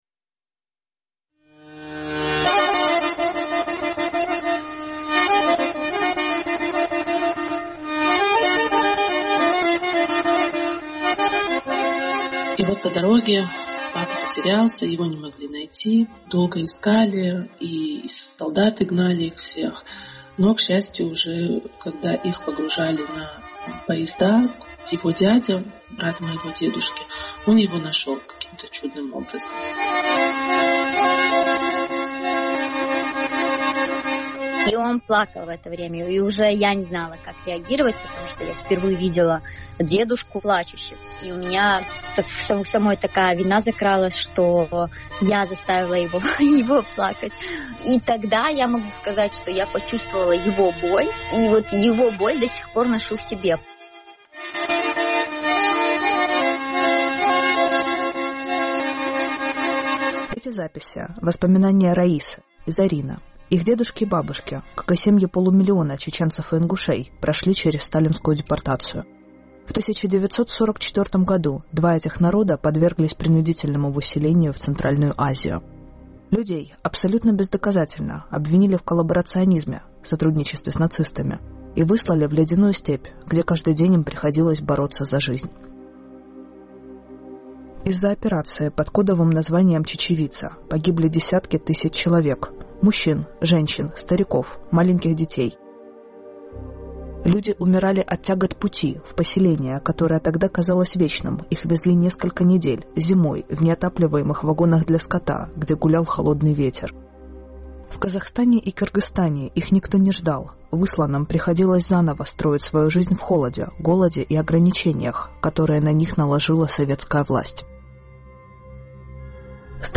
Монологи тех, чьи родители выжили в ссылке. Травма и память поколений, влияние событий 80-летней давности на жизнь сейчас.